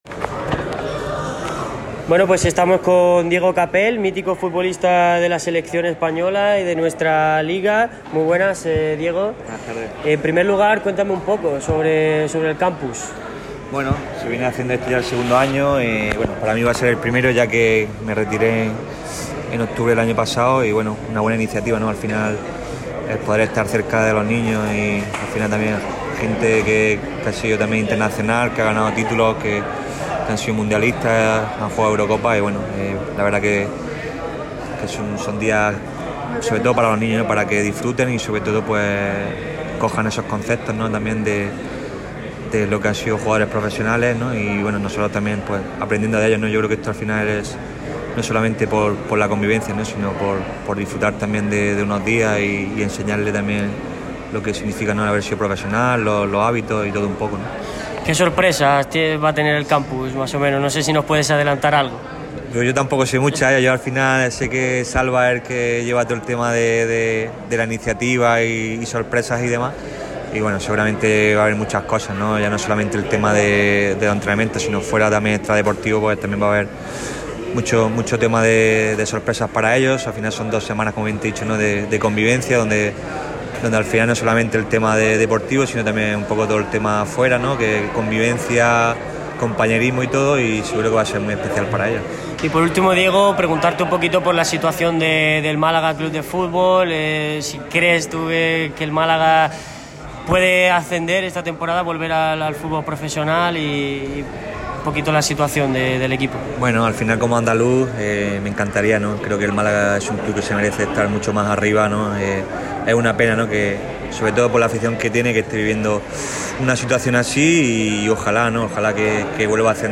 Radio MARCA Málaga ha tenido el placer de entrevistar a varios de los protagonistas, que han dejado algunas pinceladas sobre el Málaga CF. Las leyendas de la selección, se mojan sobre el Málaga CF.